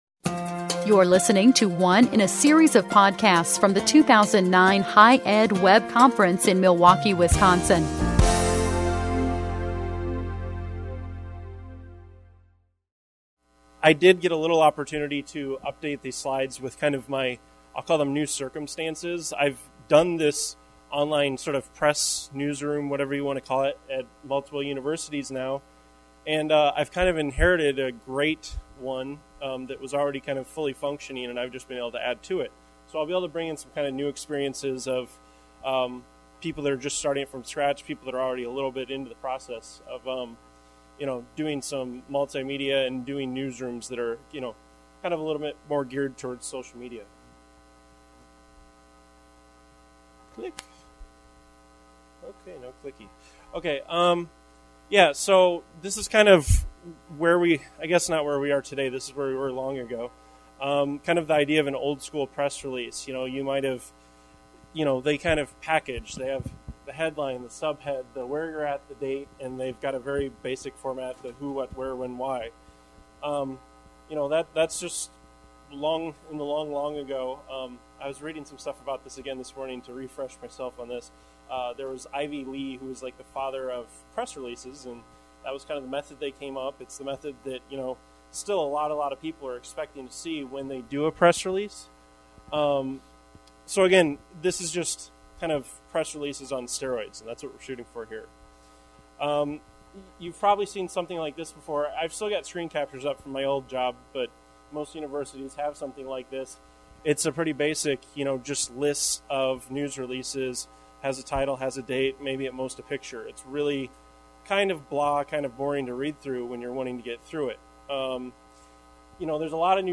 What we will explore in this session is how to, as Emeril would say, "Kick it up a notch." We will talk about how to create a press release that will draw in users and address the changing needs of the end consumer and increasing ease of use for the media.